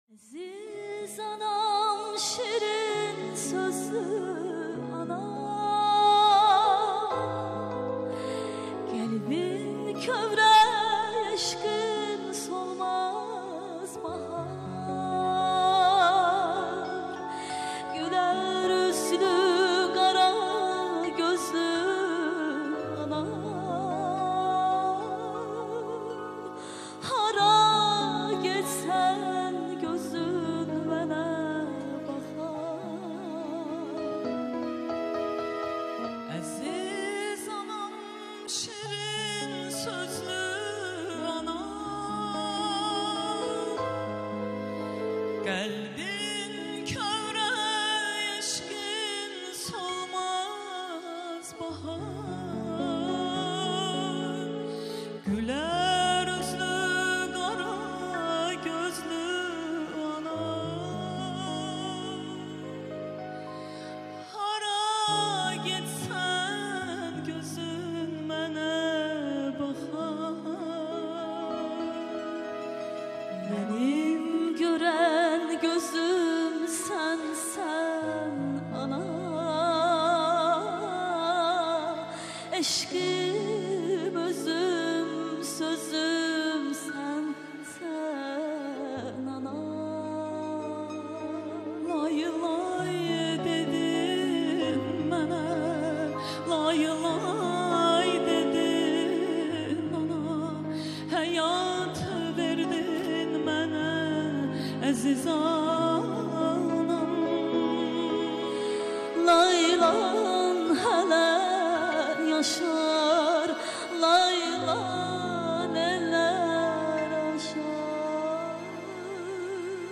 MÖHTƏŞƏM CANLI İFA